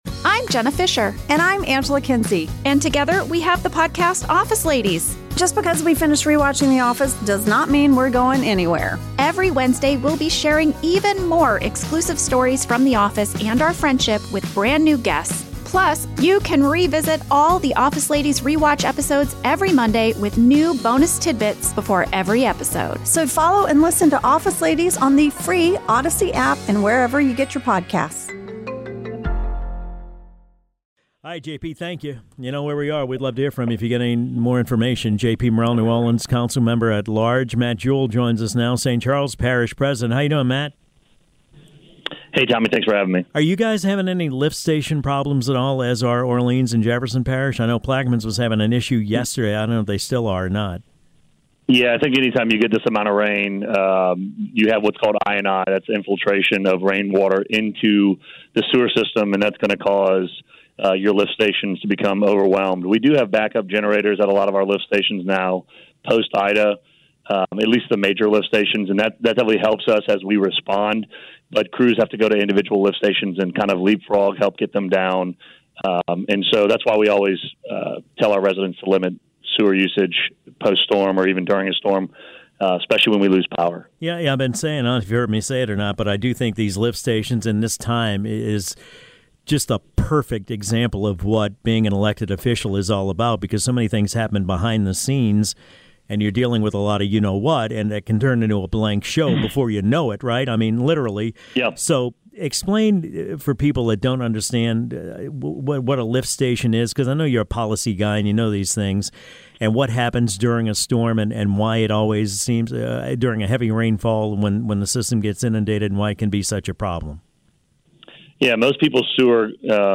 talks with Matt Jewell, President of St. Charles Parish